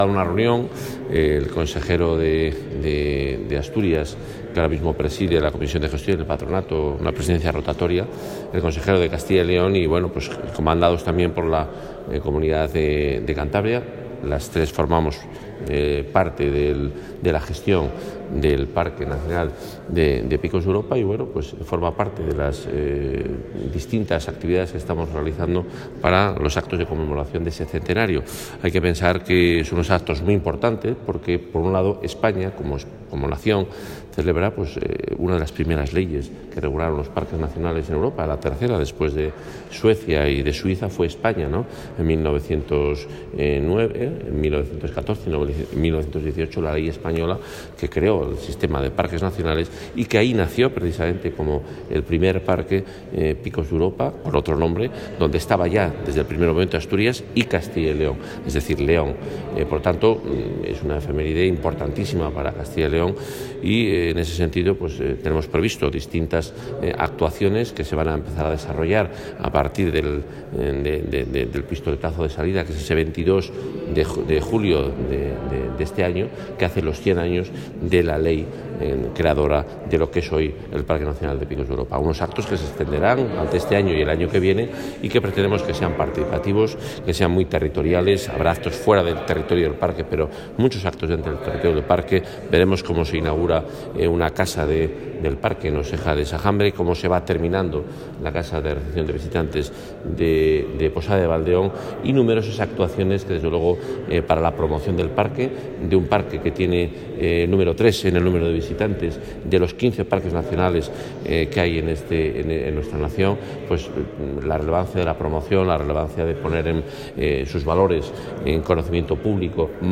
Consejero de Fomento y Medio Ambiente.